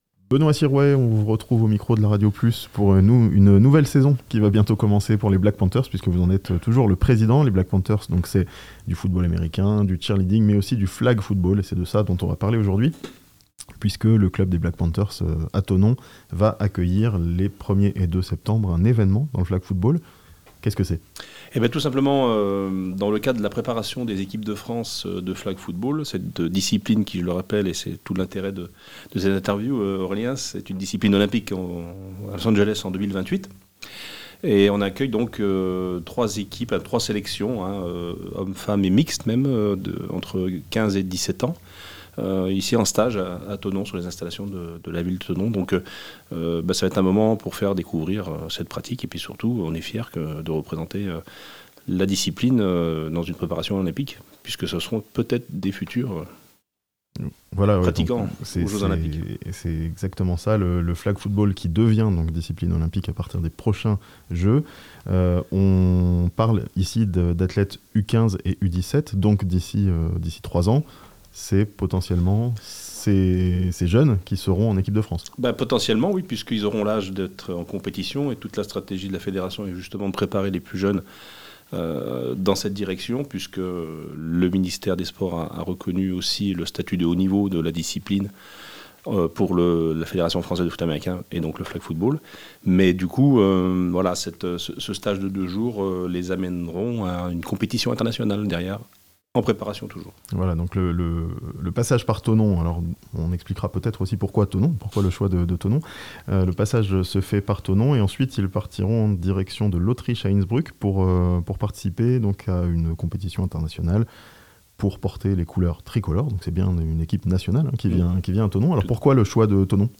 Des futurs athlètes olympiques de Flag Football en stage à Thonon (interview)